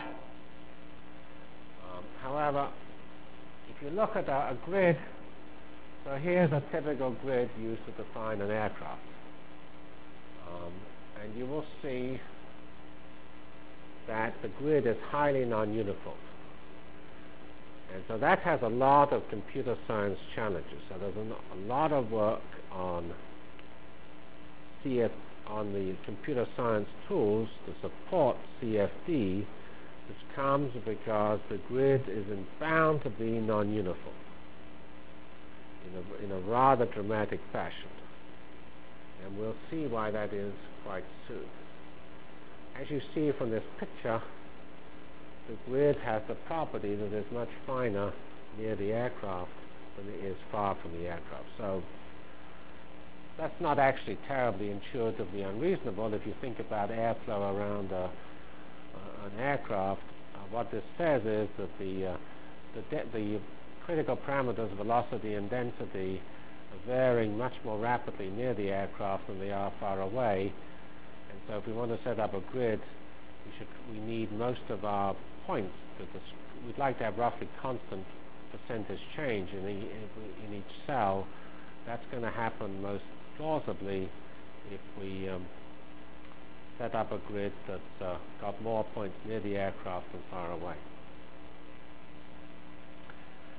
Delivered Lectures of CPS615 Basic Simulation Track for Computational Science -- 14 November 96.